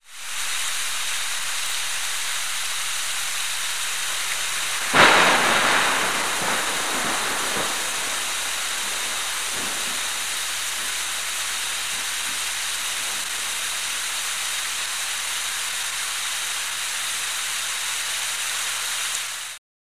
天气预报 " 雨+雷2
描述：从敞开的窗户录下雨和遥远的雷声 我用一个优化的磁带卡座和一个旧的麦克风录制了它（我录制的磁带是一个maxell UR），然后我使用audacity和相同的磁带卡座将其转换为数字。
标签： 闪电 风暴 天气 雷暴 暴雨 雷暴 自然
声道立体声